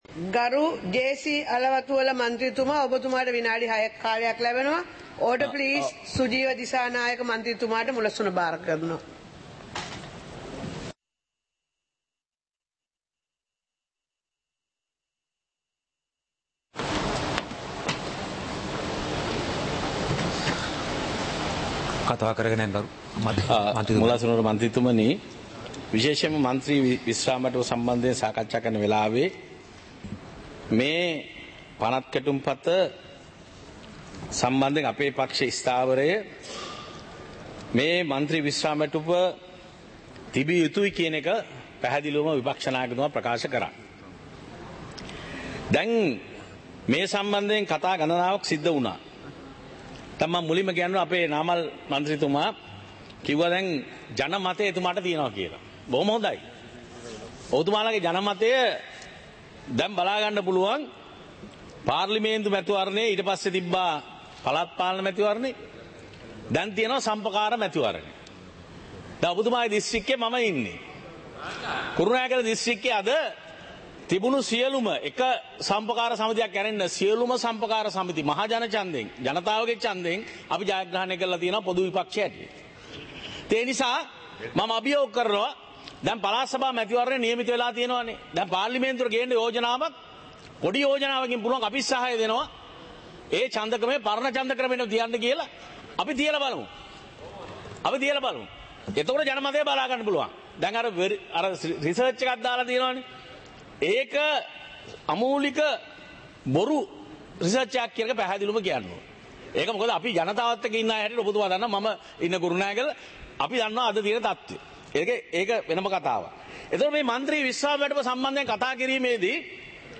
සභාවේ වැඩ කටයුතු (2026-02-17)